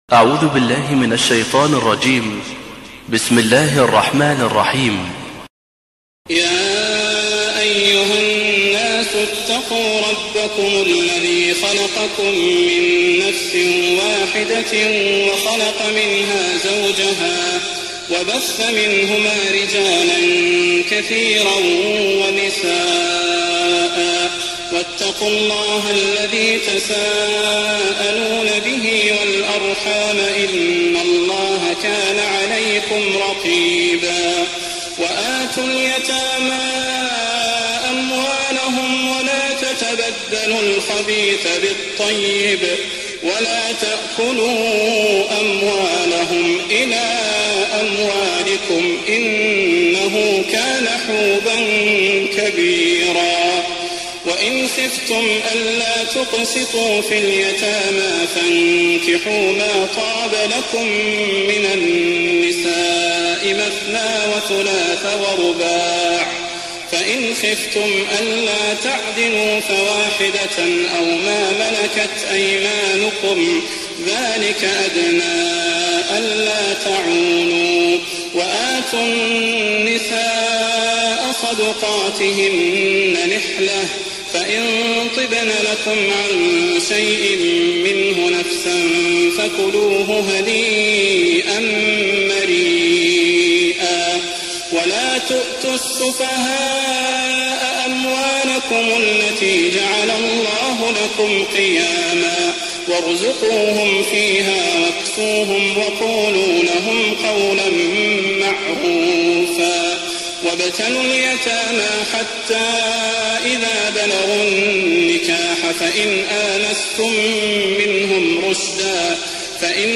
تهجد ليلة 24 رمضان 1419هـ من سورة النساء (1-24) Tahajjud 24th night Ramadan 1419H from Surah An-Nisaa > تراويح الحرم النبوي عام 1419 🕌 > التراويح - تلاوات الحرمين